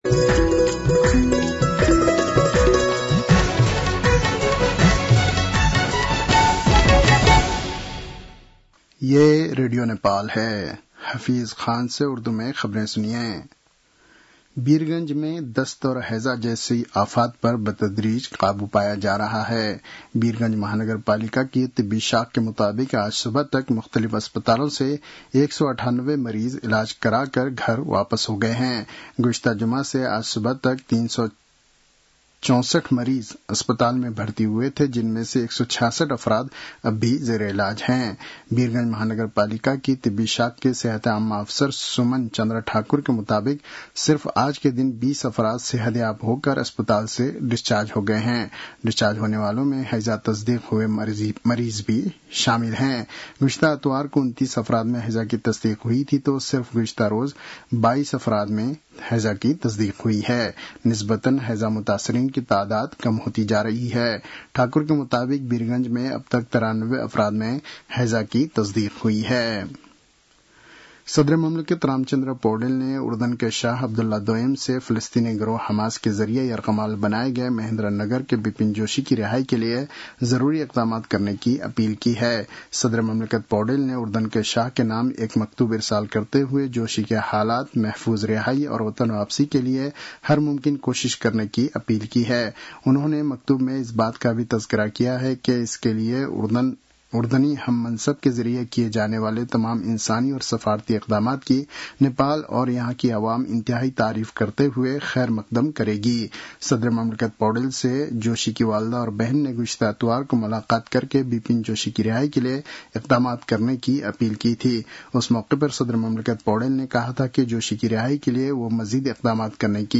उर्दु भाषामा समाचार : १० भदौ , २०८२